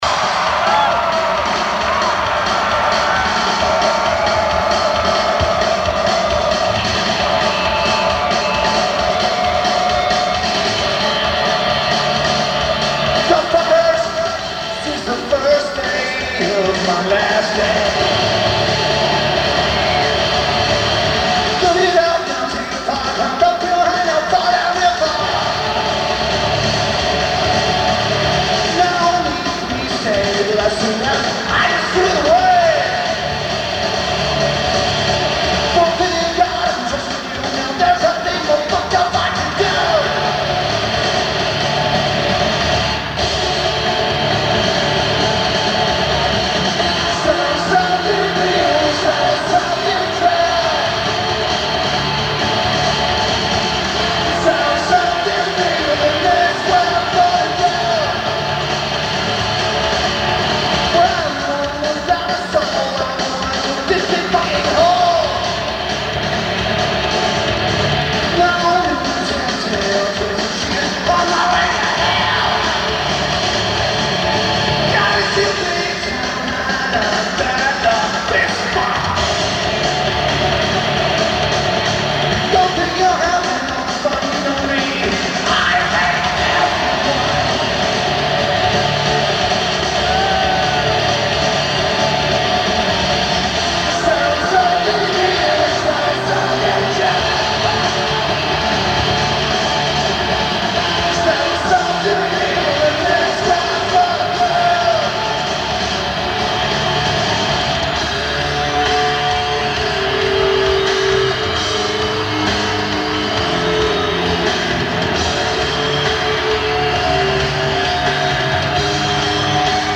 Tacoma Dome